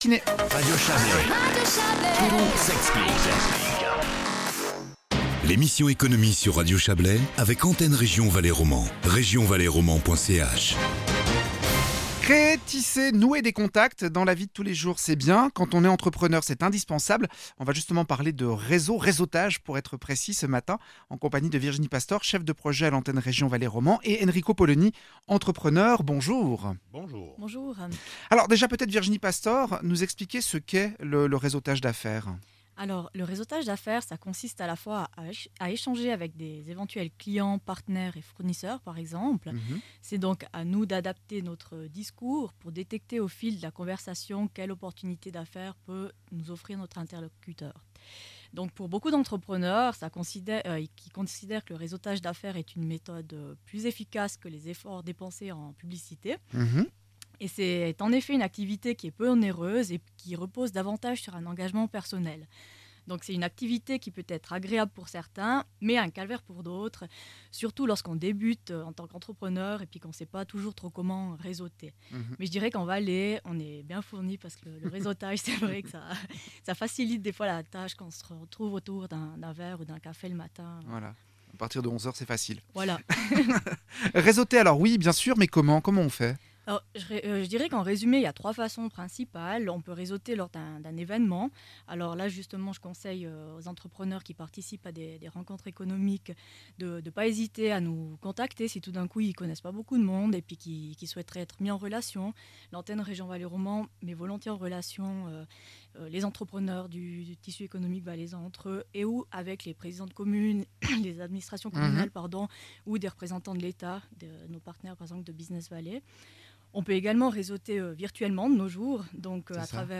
Retrouvez les rubriques économiques de l'Antenne Région Valais romand sur Radio Chablais.